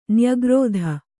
♪ nyagrōdha